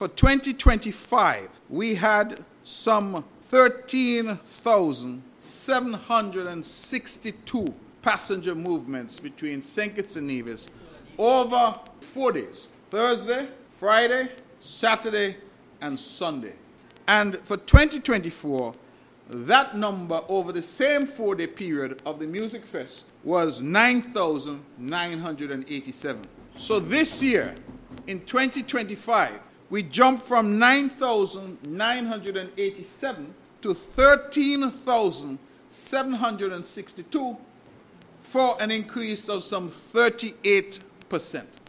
The voice of Premier of Nevis, Hon. Mark. Brantley during his presentation in a sitting of the Nevis Island Assembly on Thursday July 3rd.